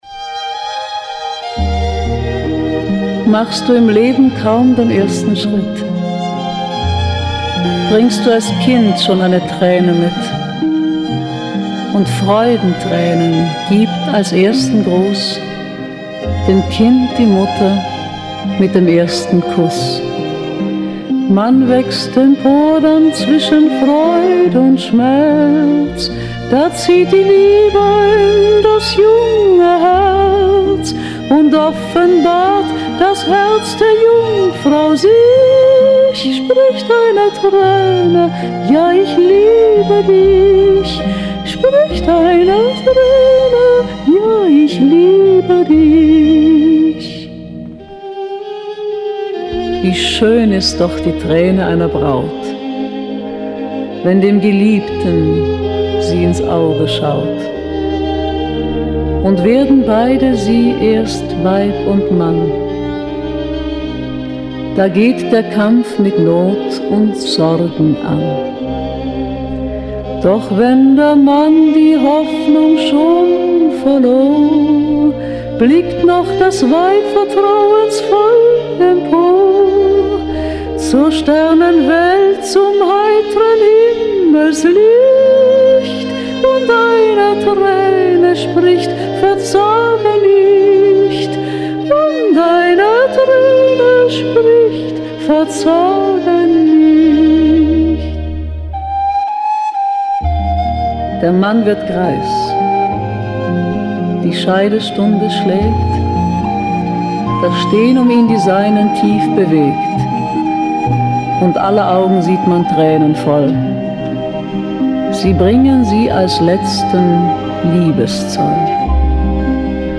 Inhalt: 12 Volkslieder gesungen von Maria Schell, einem der größten Stars des deutschen Films der 1950er und 1960er Jahre